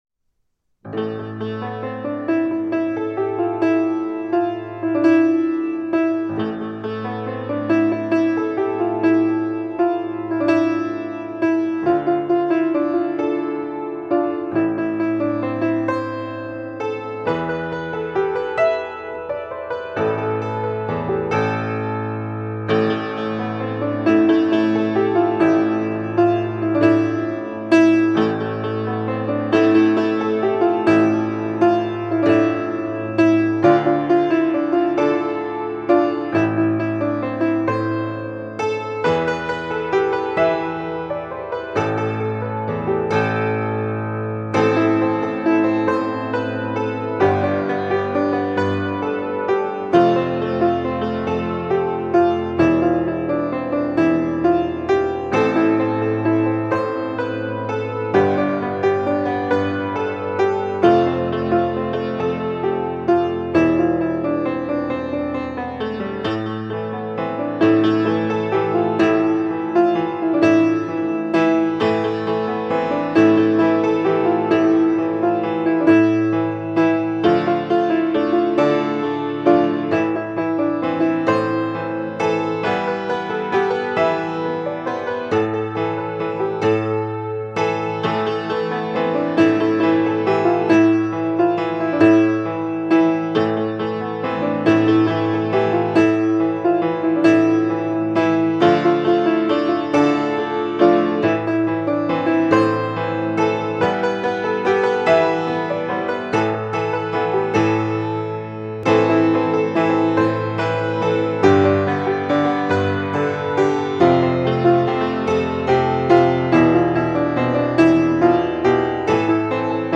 1. Piano